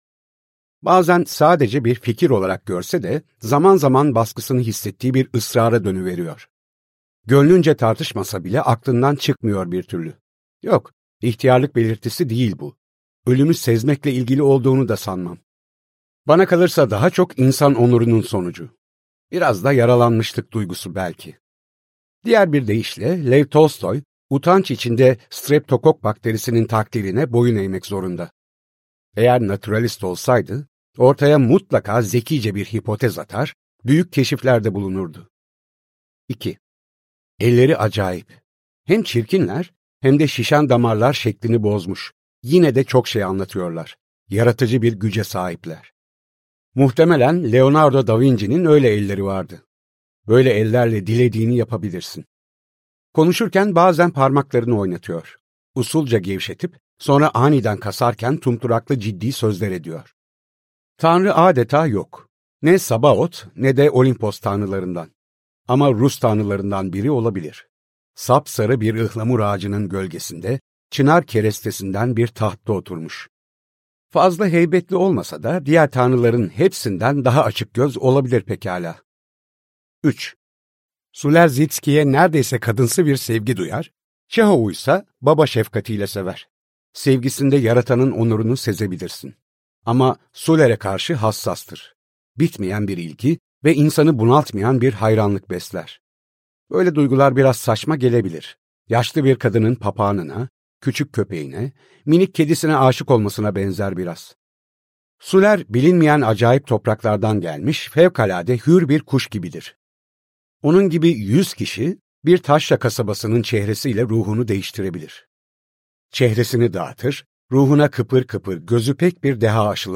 Savaş ve Barış - Seslenen Kitap